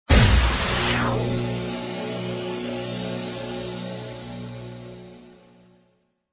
Section#1-Sweepers, sound effects
All tracks encoded in mp3 audio lo-fi quality.
soft sweep fx